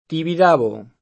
vai all'elenco alfabetico delle voci ingrandisci il carattere 100% rimpicciolisci il carattere stampa invia tramite posta elettronica codividi su Facebook Tibidabo [ tibid # bo ; sp. ti B i D#B o ] top. m. (a Barcellona)